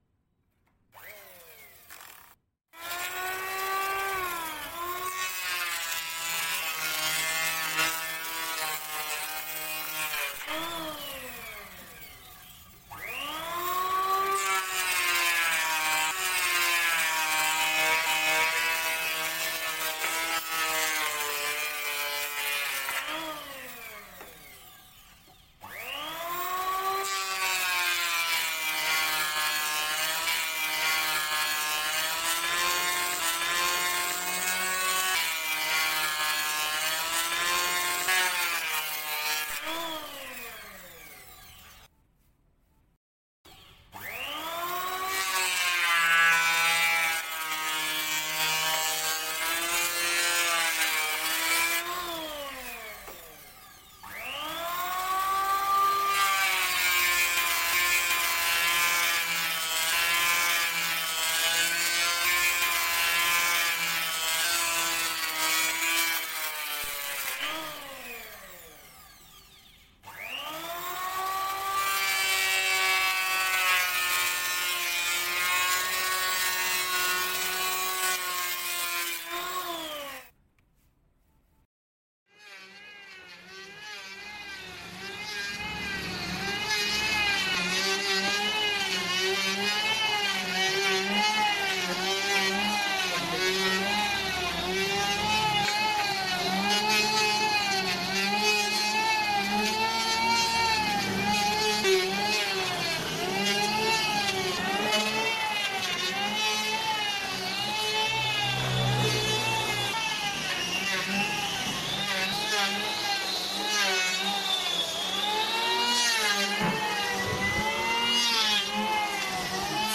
Tổng hợp tiếng Bào Gỗ bằng Máy bào cầm tay
Thể loại: Tiếng động
Description: Tổng hợp các âm thanh tiếng Bào Gỗ bằng Máy bào cầm tay mp3 là hiệu ứng âm thanh ghi lại quá trình gia công gỗ thủ công, với âm thanh lưỡi bào ma sát trên bề mặt gỗ mộc mạc và chân thực.
tong-hop-tieng-bao-go-bang-may-bao-cam-tay-www_tiengdong_com.mp3